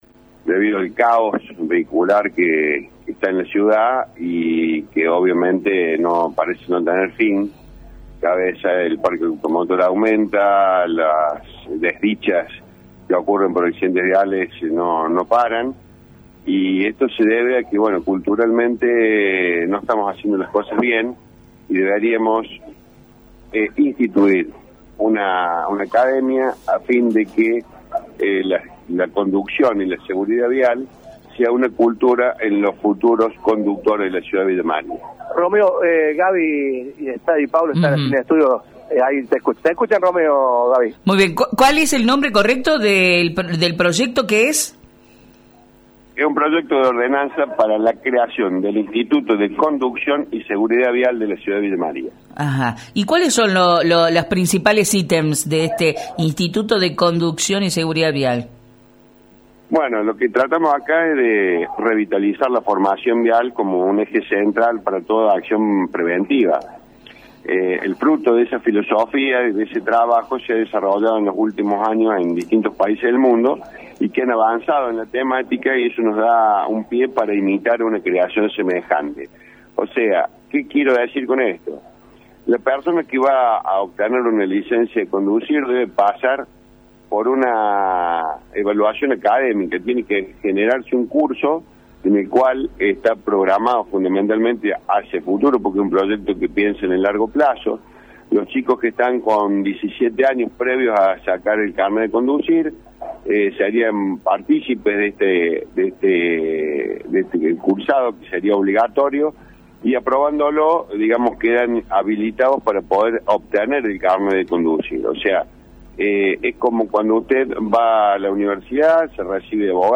Esta mañana, el edil de Vamos Villa María, contó en Radio Centro el proyecto de ordenanza que han propuesto desde su bloque para crear un Instituto de Conducción y Seguridad Vial en la ciudad de Villa María.